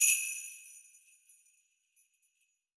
Sleigh Bells (1).wav